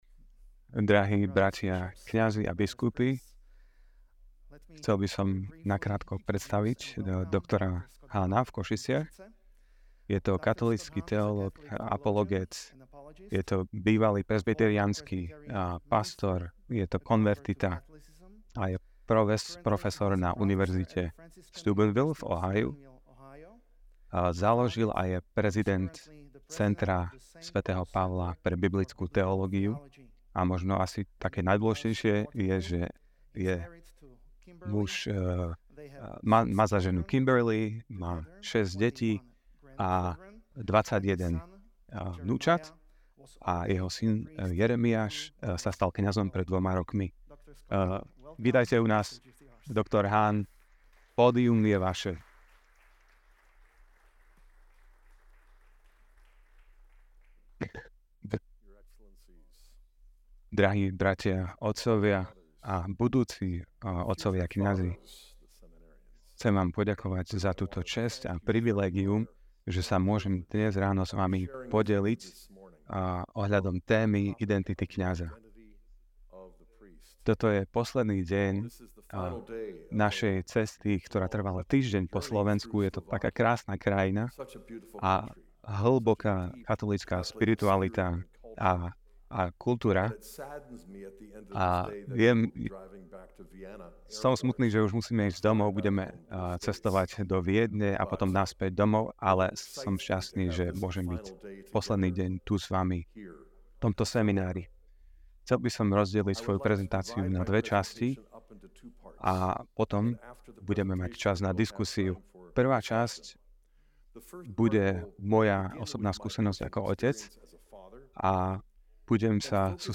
Kňazská konferencia Scott Hahn | Prednáška | SK